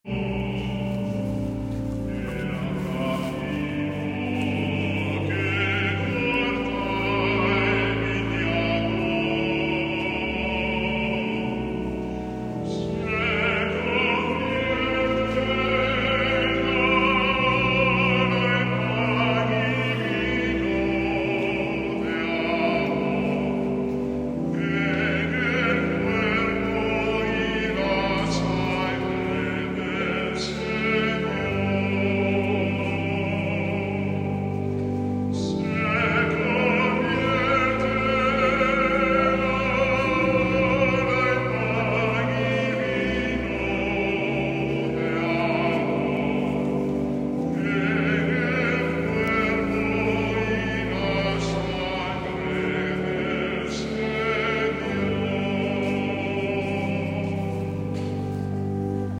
From the Cathedral practice